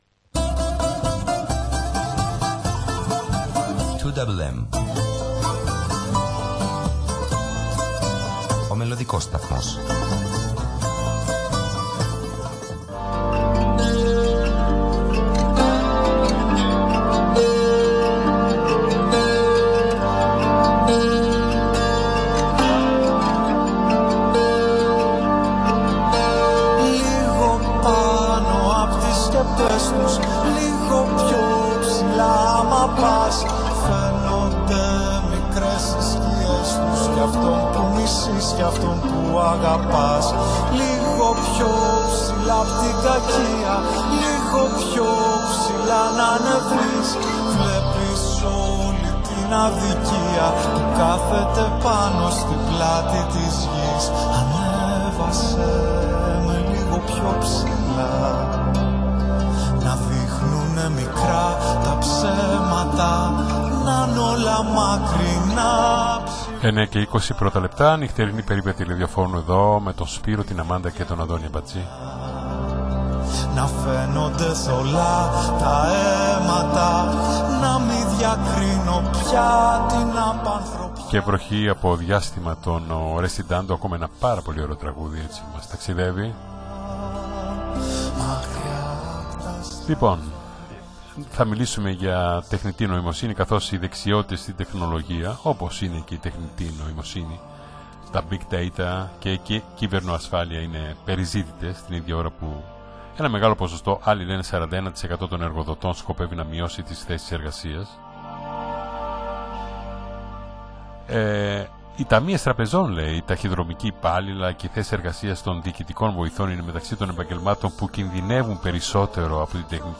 ζωντανής του συνέντευξης